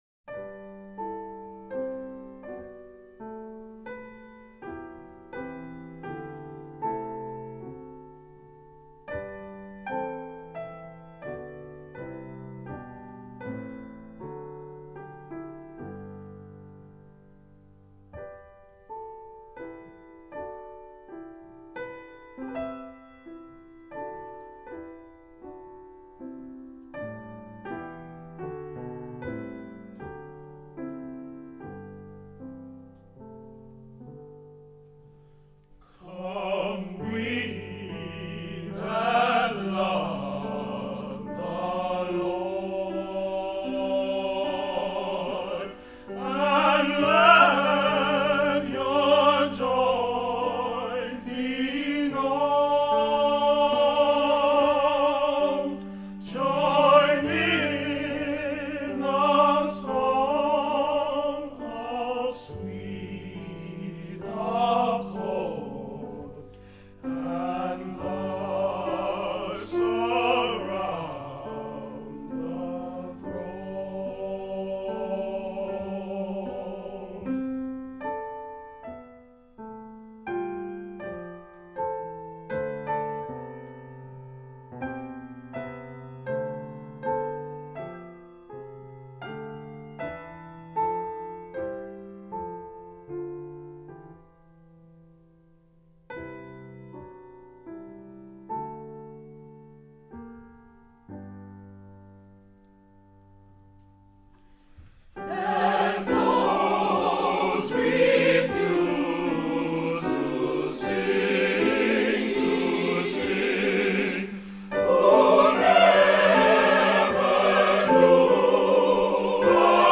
Accompaniment:      With Organ
Music Category:      Christian